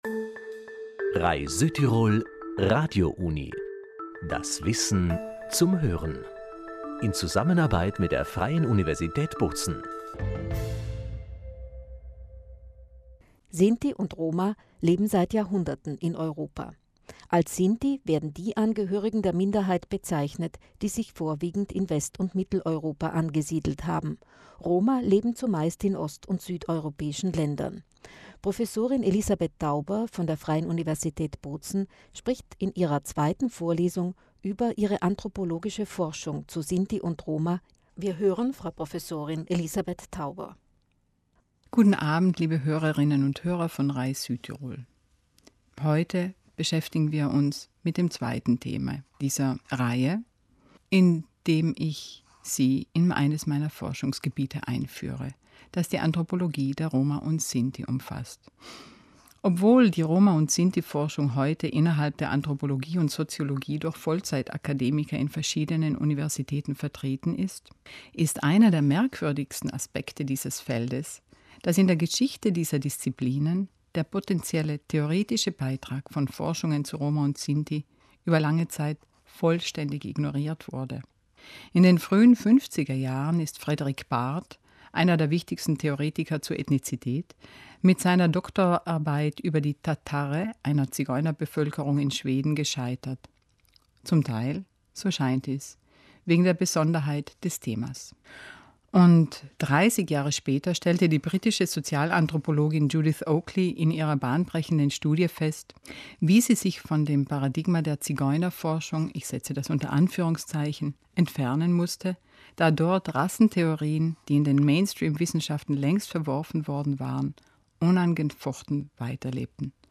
Dreiteilige Vorlesungsreihe zur sozialen Anthropologie